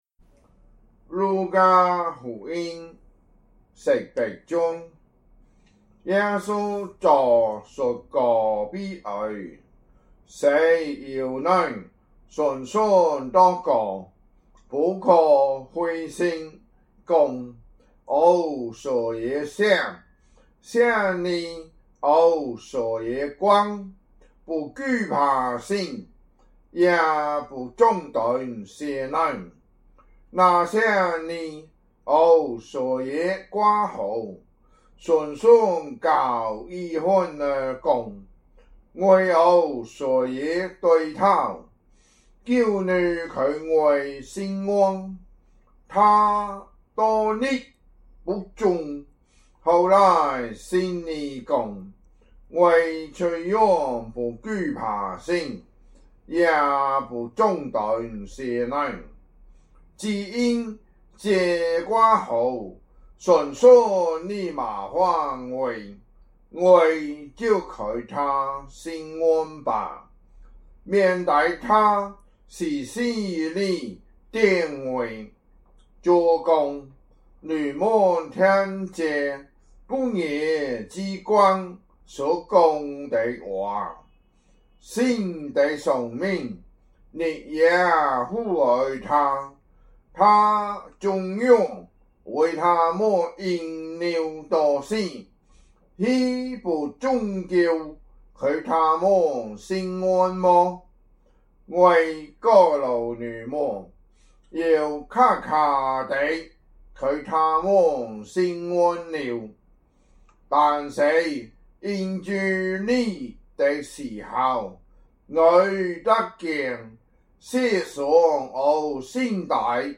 福州話有聲聖經 路加福音 18章